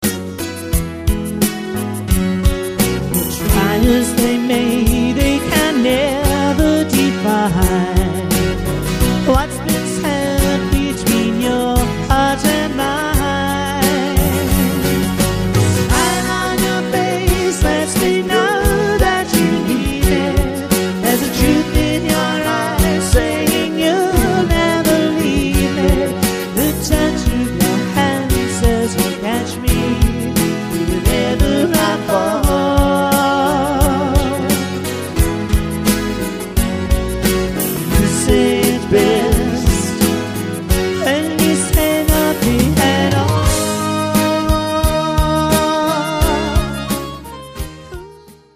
Party - Beach - Classics - Country -Pop - Rock - Romantics - Latin Fun
Their music is just as varied with backgrounds of folk & country, pop & rock, plus a tropical influence from their location.
add to the above live percussion player